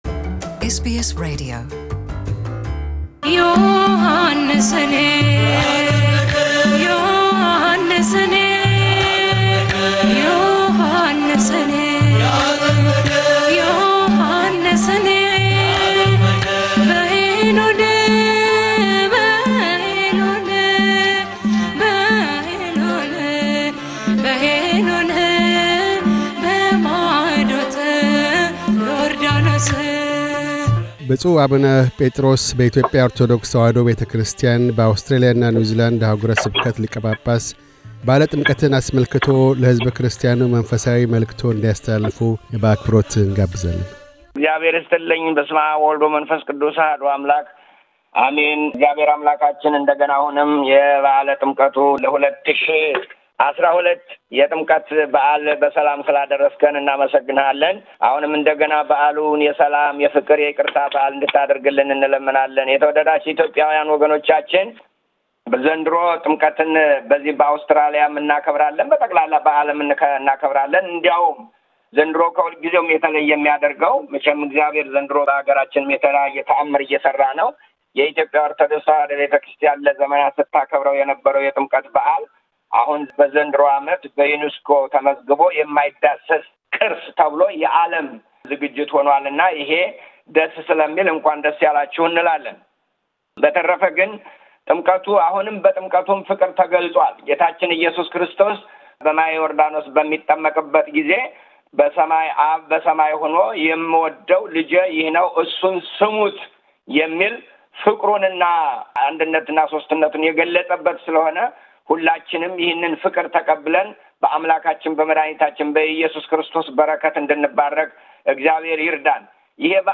ብፁዕ አቡነ በኢትዮጵያ ኦርቶዶክስ ተዋህዶ ቤተክርስቲያን - በአውስትራሊያና ኒውዚላንድ አኅጉረ ስብከት ሊቀ ጳጳስ፤ የበዓለ ጥምቀት መንፈሳዊ መልዕክታቸውን ለእምነቱ ተከታዮች ያስተላልፋሉ። በአውስትራሊያ ደርሶ ያለውን የእሳት አደጋ አስመልክተውም ኢትዮጵያውያን በያሉበት የልገሳ እጆቻቸውን እንዲዘረጉ ጥሪ ያቀርባሉ።